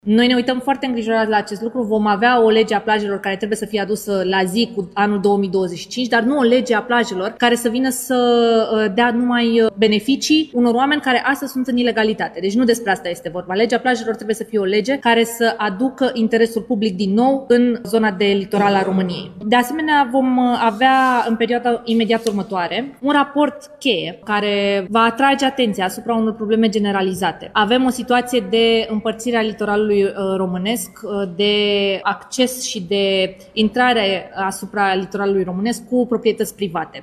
Ministra Mediului, Diana Buzoianu: „Vom avea o lege a plajelor care va fi la zi cu anul 2025”